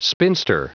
Prononciation du mot spinster en anglais (fichier audio)
Prononciation du mot : spinster